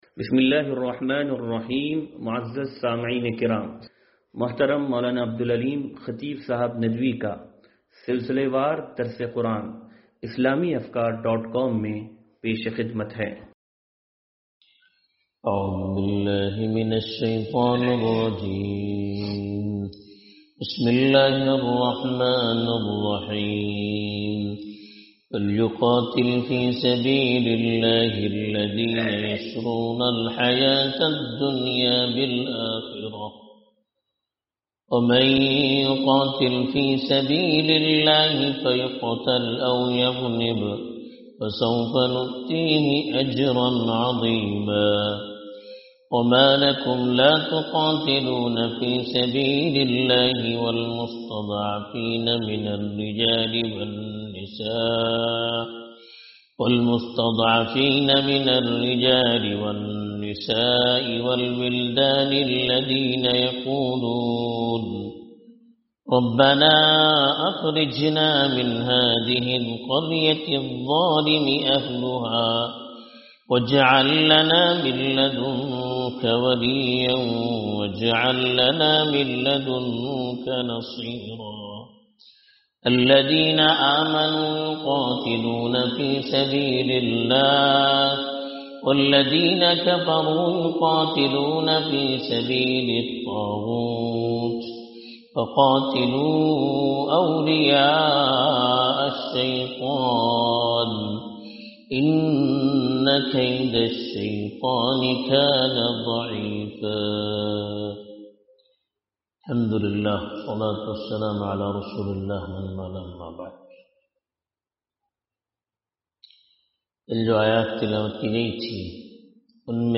درس قرآن نمبر 0367